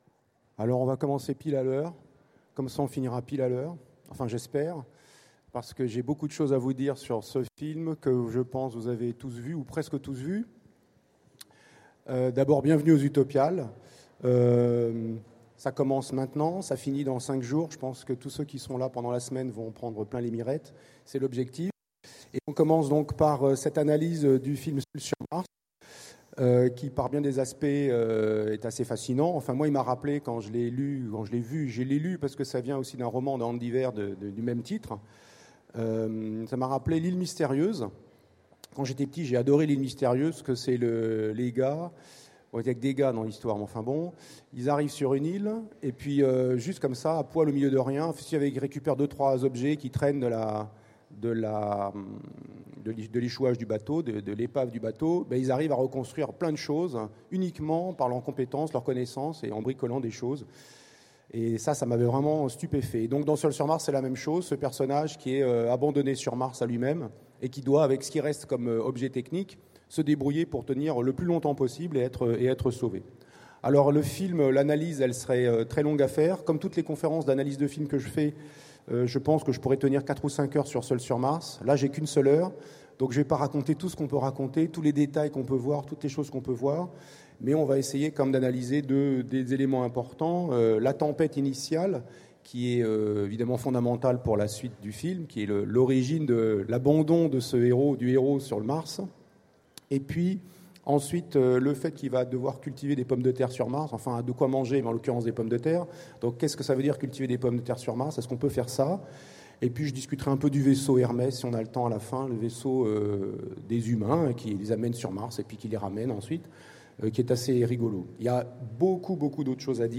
Utopiales 2016 : Conférence Peut-on vivre « Seul sur Mars »?